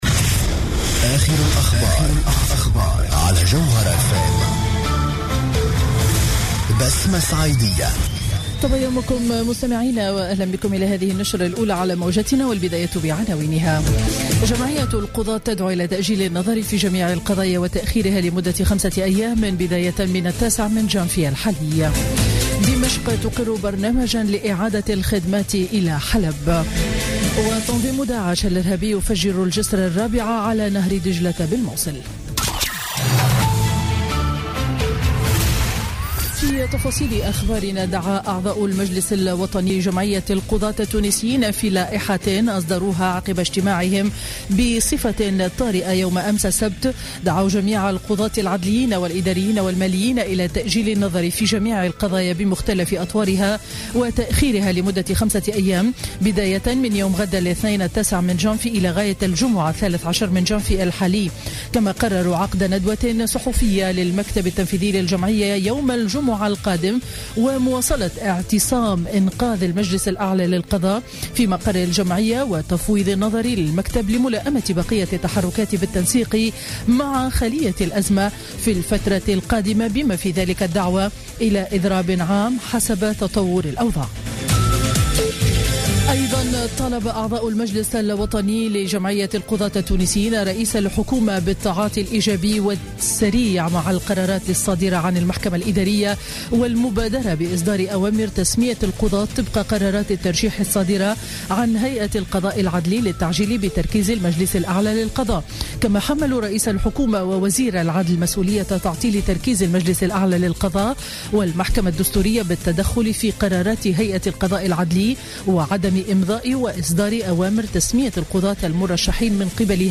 نشرة أخبار السابعة صباحا ليوم الأحد 8 جانفي 2017